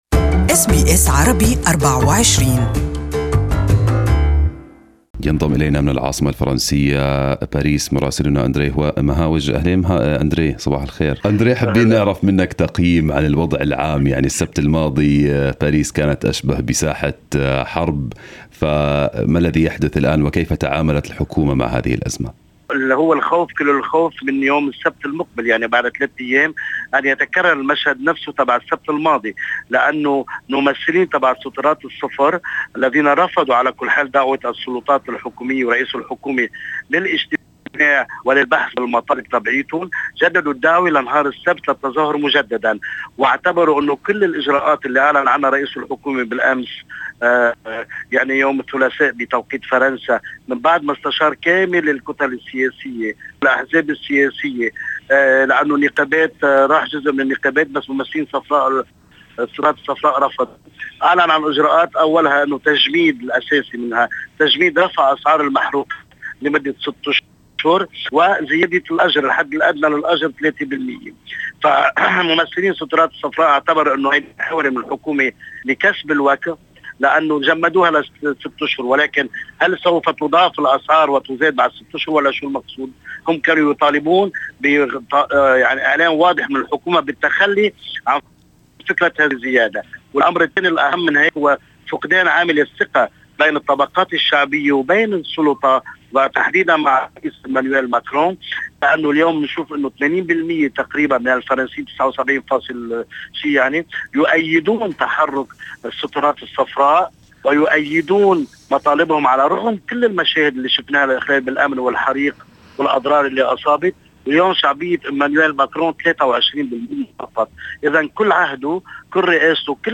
Listen to the full report for our correspondent in Arabic above